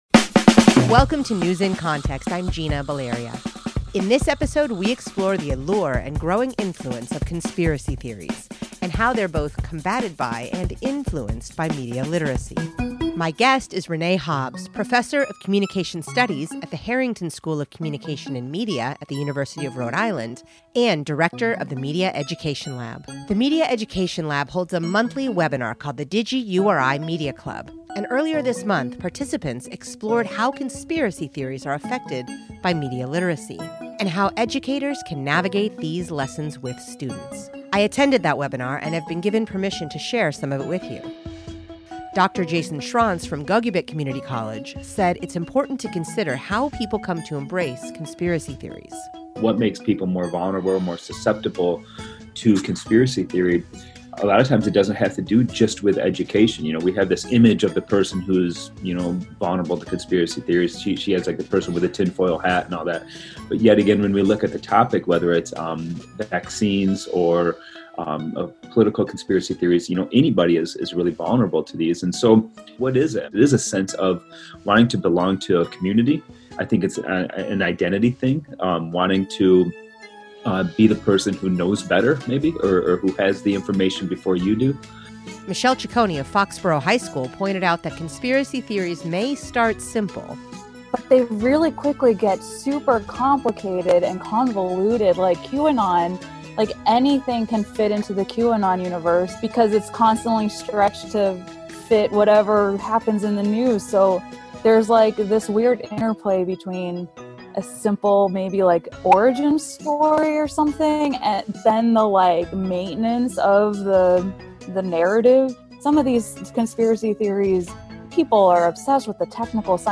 This is part one of a two-part interview.